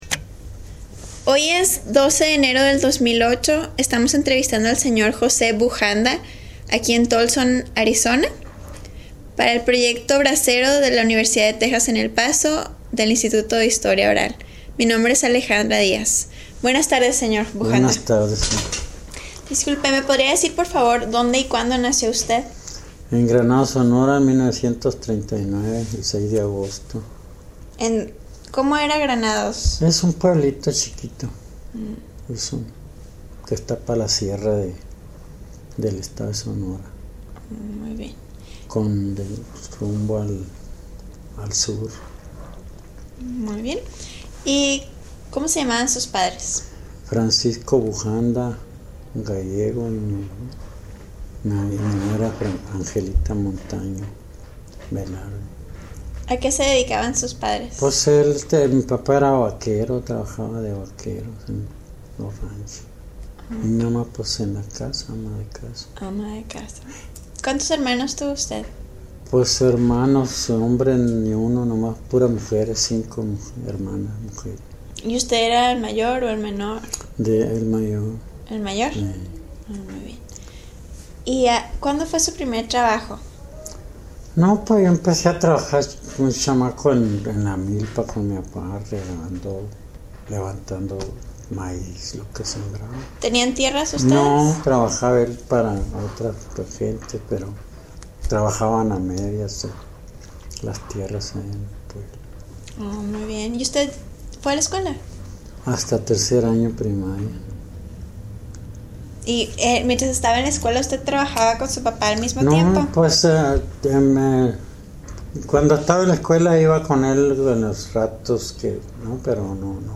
Location Tolleson, Arizona